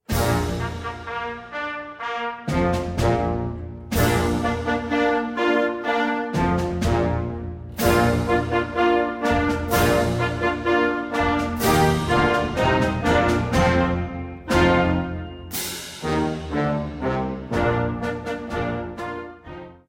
Gattung: Konzertmarsch
Besetzung: Blasorchester
Bb Major.